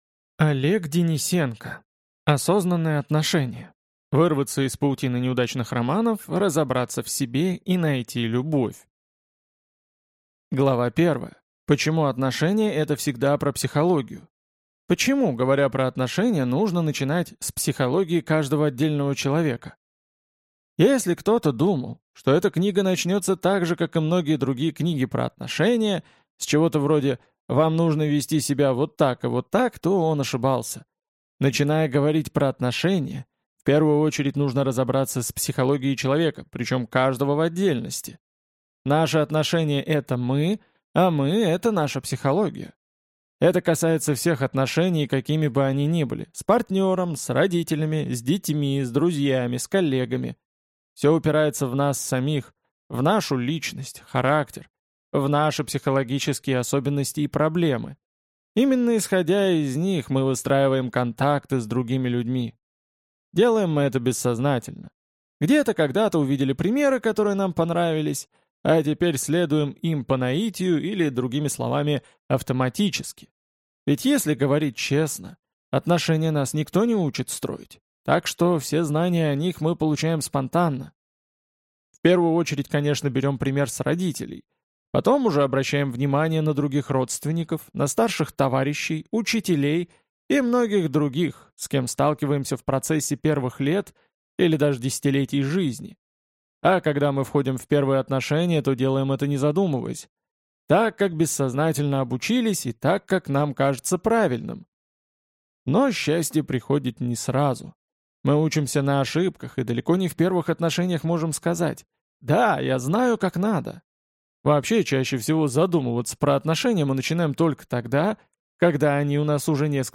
Аудиокнига Осознанные отношения. Вырваться из паутины неудачных романов, разобраться в себе и найти любовь | Библиотека аудиокниг